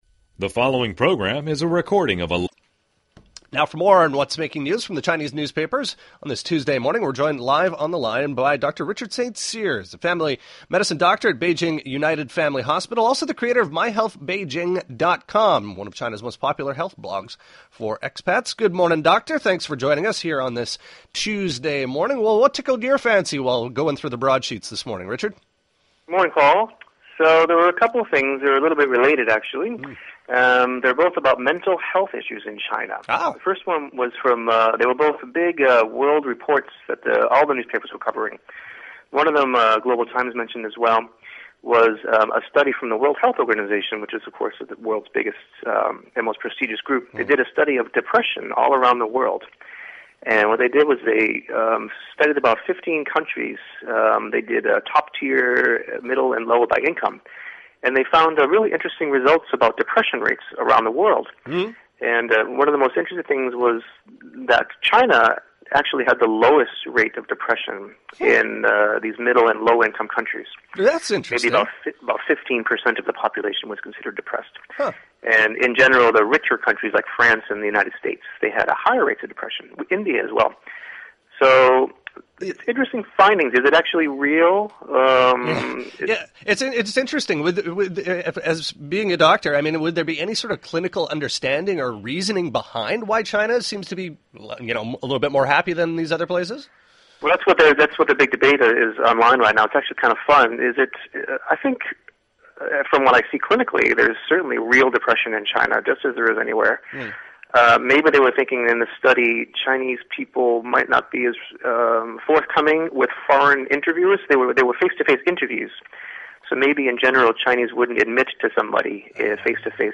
EZFM is the popular bilingual radio station on the CRI Radio network, broadcasting here in Beijing and on multiple stations all over the world, as well as live online here.